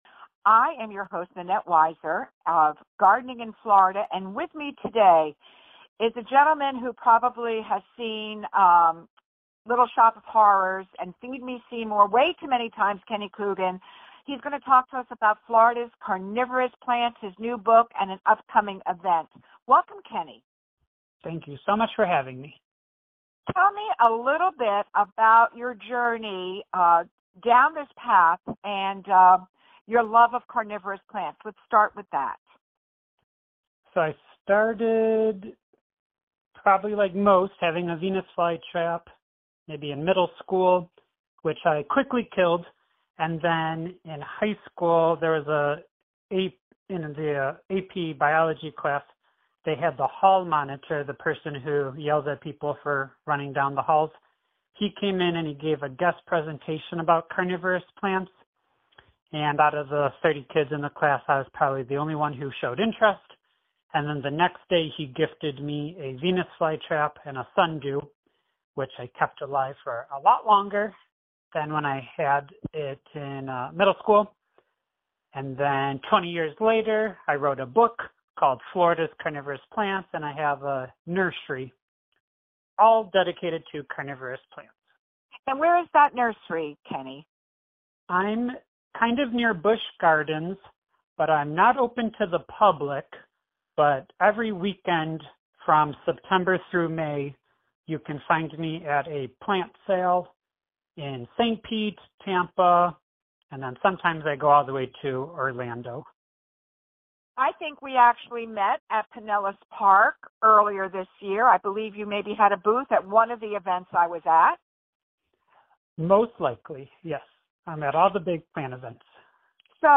Radio St. Pete Podcast Archive